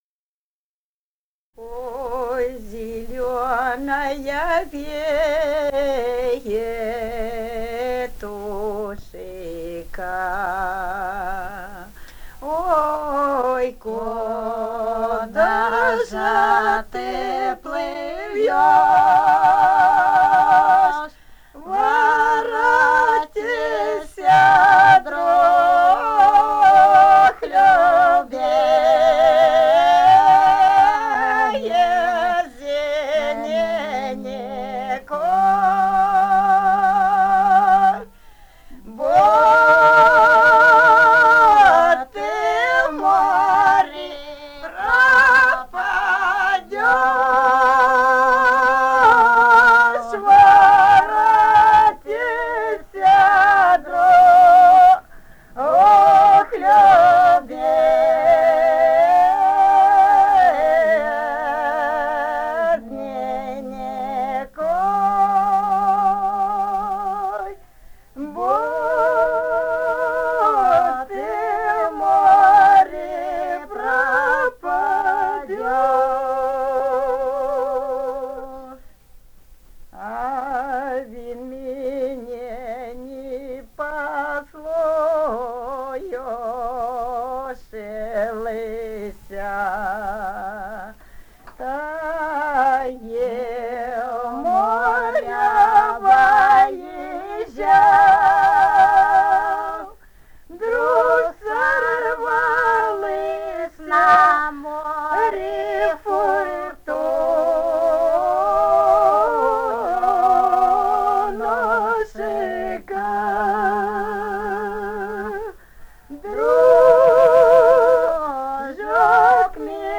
Этномузыкологические исследования и полевые материалы
«Ой, зелёная ветушка» (романс).
Румыния, с. Переправа, 1967 г. И0973-02